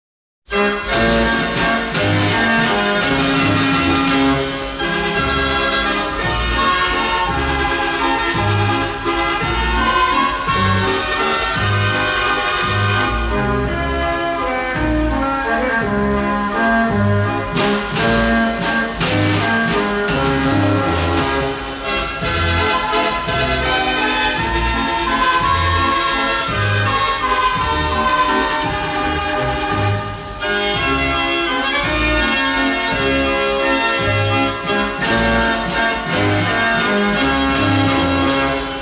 Mortier Belgian Dance Organ